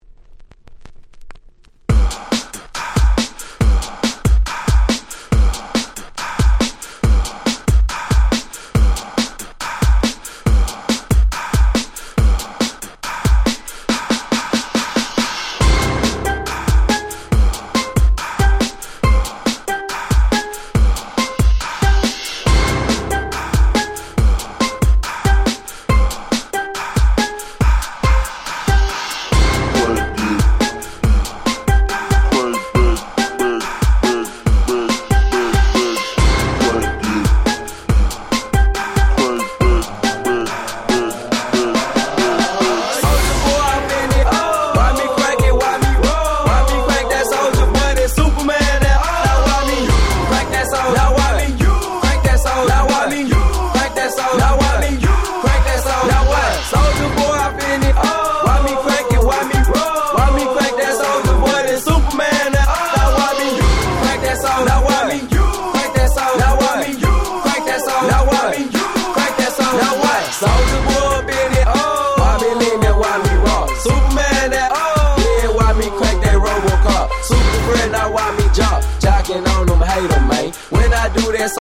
07' Super Hit Southern Hip Hop !!